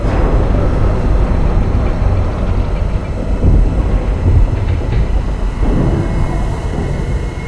Alarm2_3.ogg